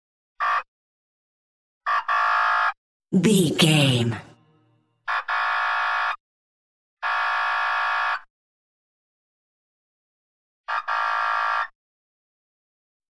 Police Car Horn
Sound Effects
urban
chaotic
emergency